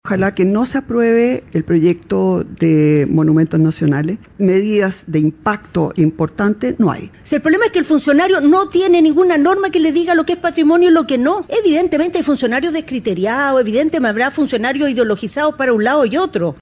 Debate Icare por permisología: Carolina Tohá y Evelyn Matthei